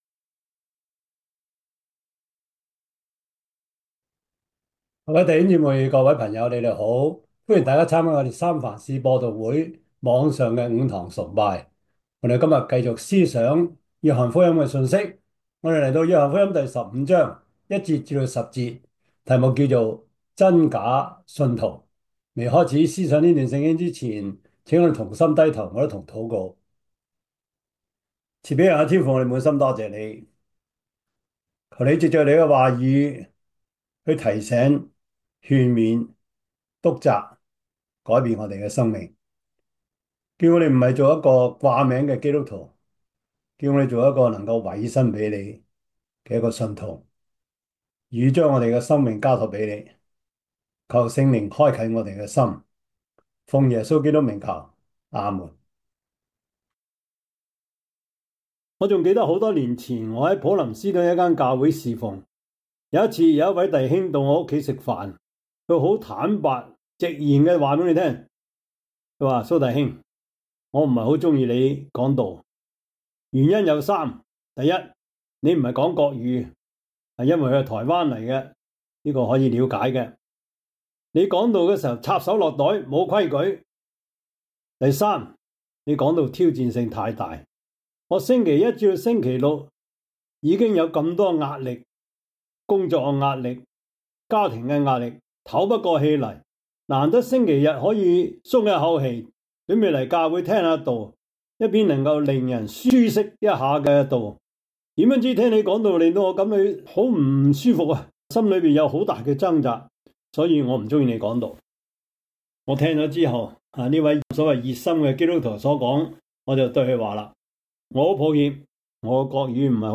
約翰福音 15:1-10 Service Type: 主日崇拜 約翰福音 15:1-10 Chinese Union Version